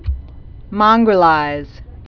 (mŏnggrə-līz, mŭng-)